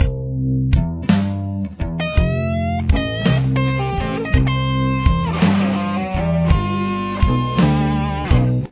Lick 4
Let the last 4 notes on the 10th fret ring together.